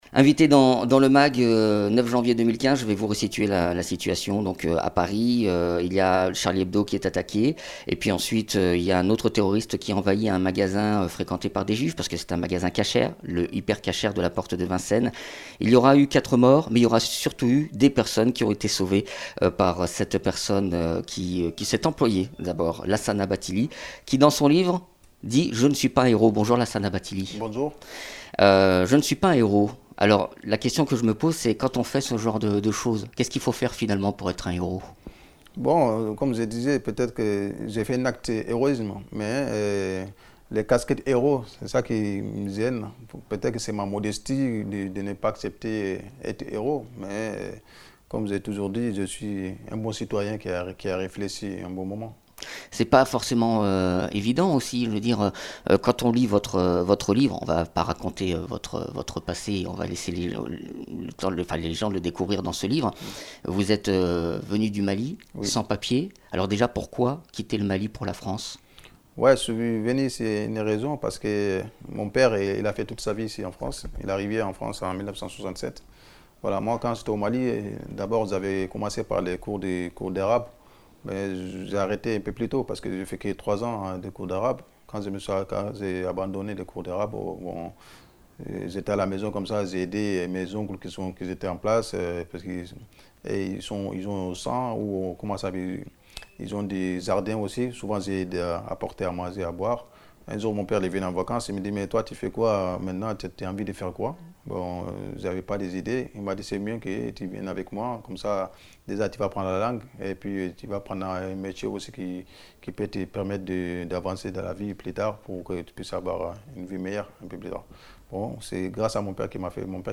Interviews
Invité(s) : Lassana Bathily, auteur